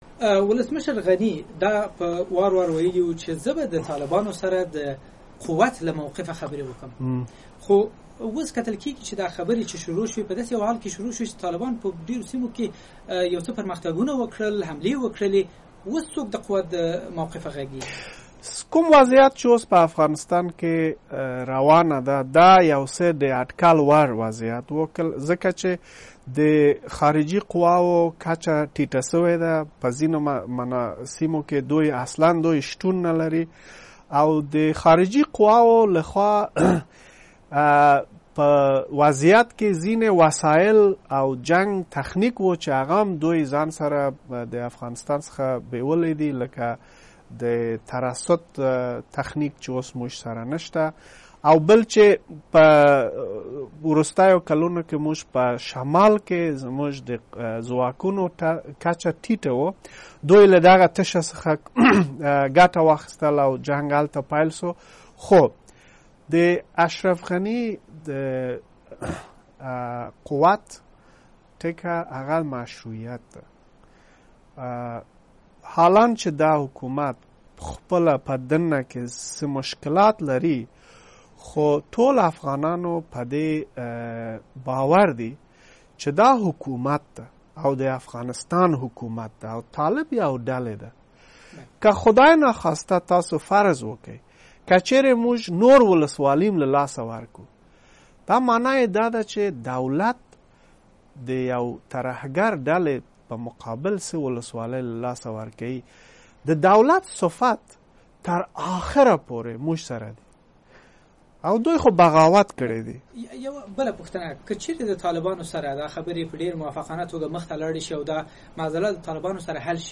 مرکې
امر الله صالح ددې کنفرانس وروسته په واشنگټن ډي سي کې د امریکا غږ مرکزي دفتر راغی او په یوه ځانگړې مرکه کې یې د افغانستان د حکومت او طالبانو ترمنځ د سولې د خبرو په گډون په یو لړ موضوعگانو خبرې وکړې.